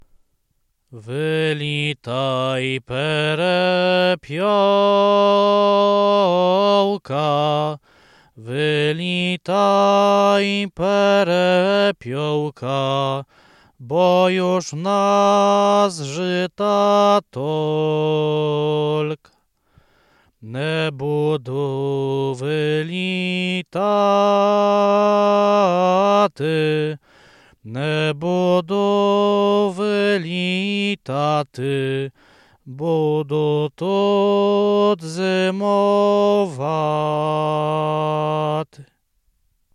Dożynkowa